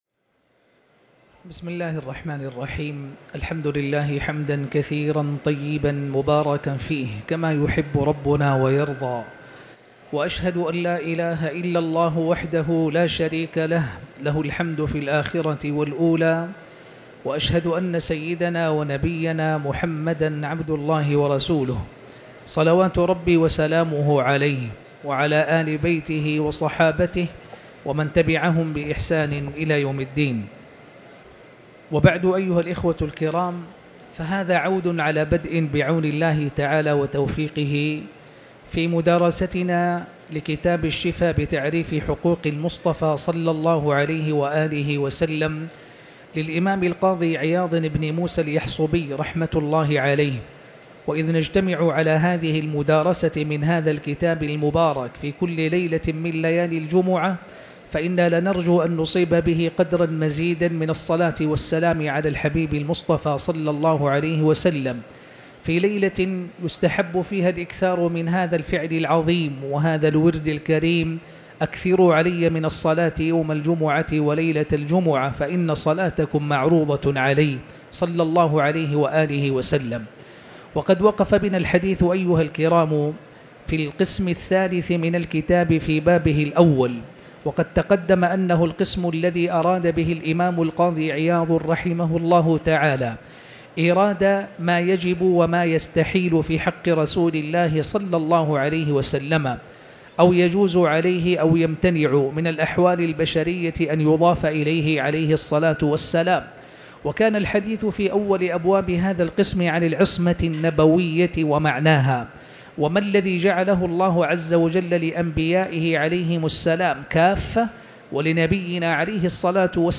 تاريخ النشر ٢٨ ذو الحجة ١٤٤٠ هـ المكان: المسجد الحرام الشيخ